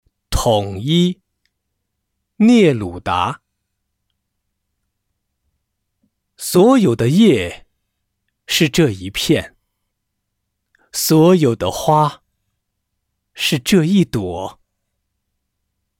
九语下3 短诗五首-统一 课文朗读（素材）